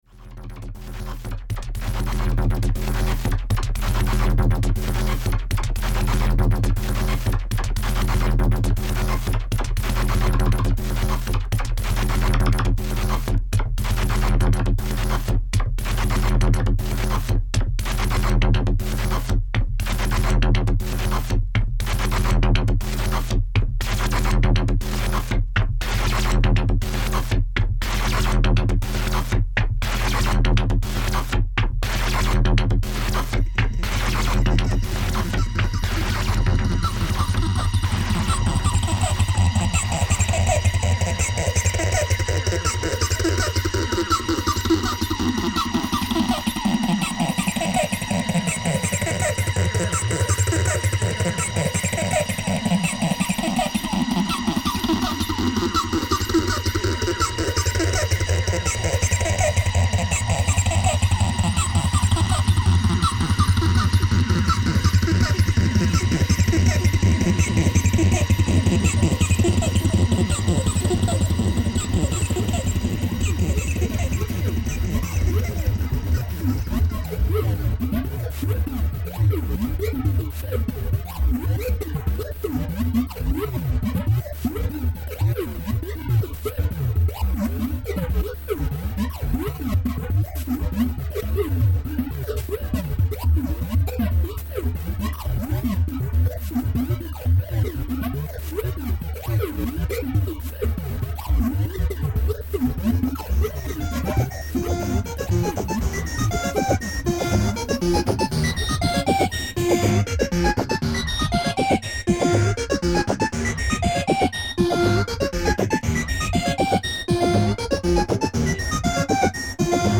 In: acid, experimental, techno, top2011
B-side is more experimental and again the second track